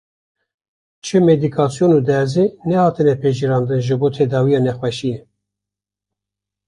/dɛɾˈziː/